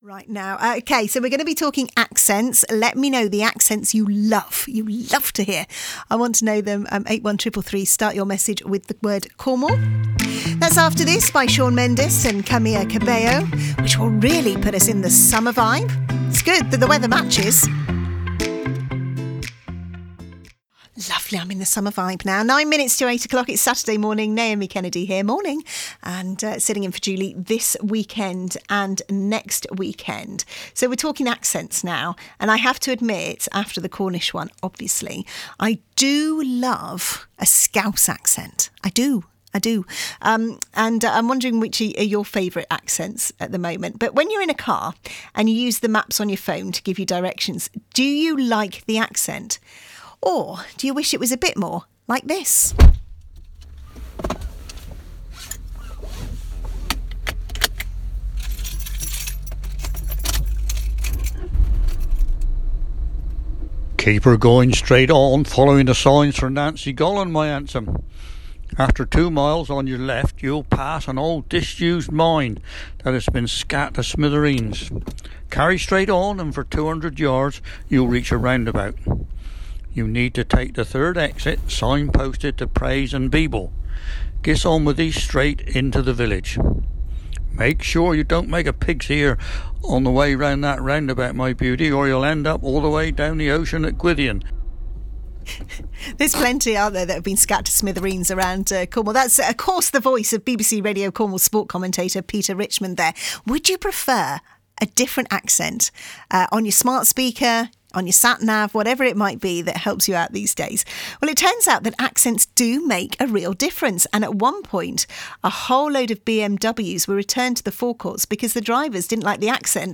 BBC Radio Cornwall Interview.mp3